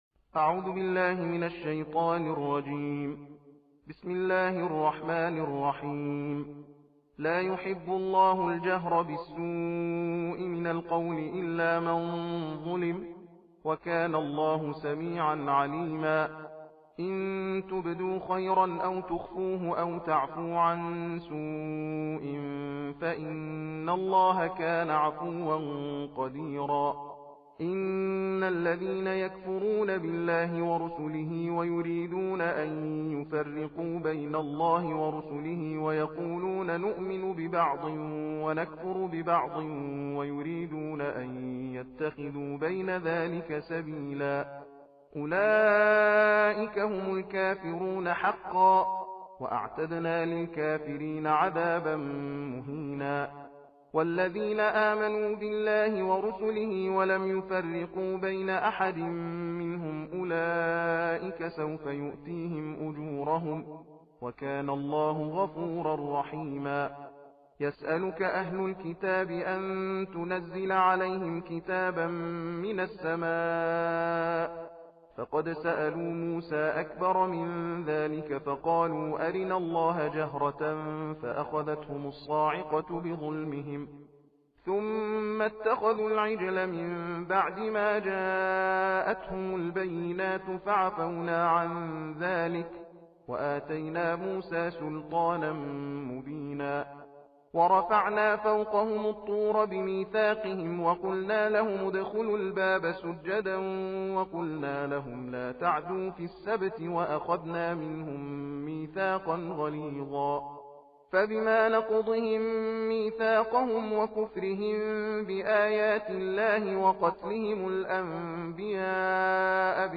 القرآن الكريم: تلاوة الجزء السادس من القرآن الكريم ..